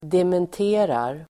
Uttal: [dement'e:rar]